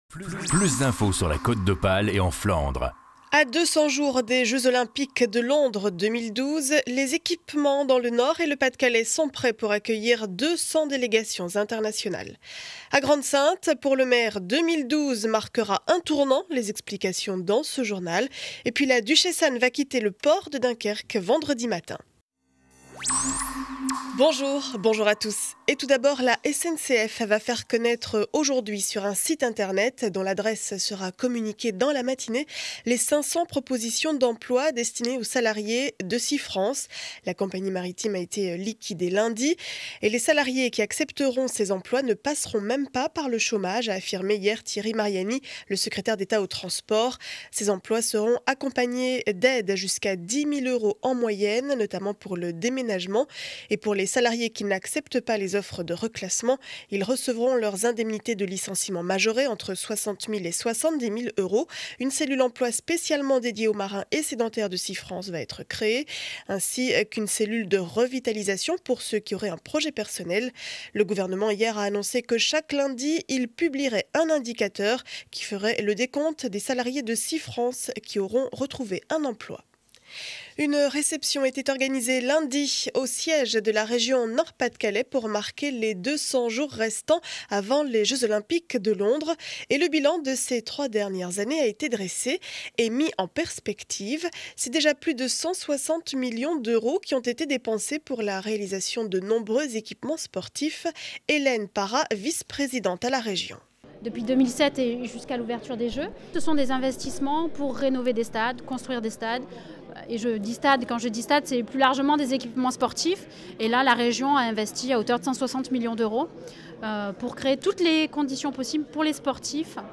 Journal du mercredi 11 janvier 7 heures 30 édition du Dunkerquois.